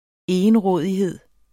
Udtale [ ˈejənˌʁɔˀðiˌheðˀ ]